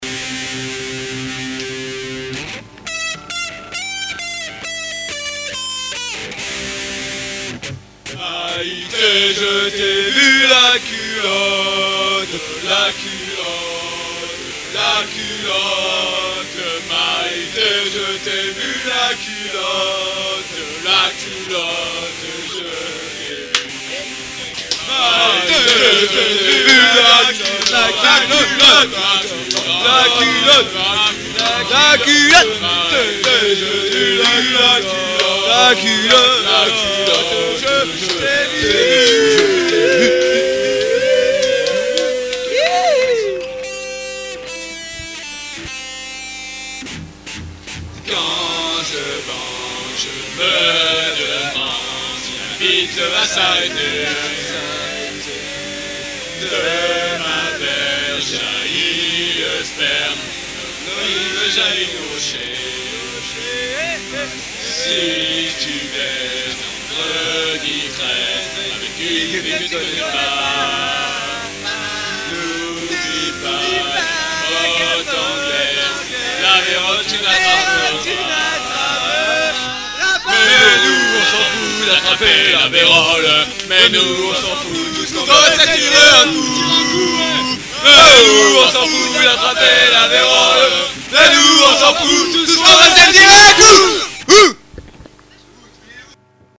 Medley paillard
création très rock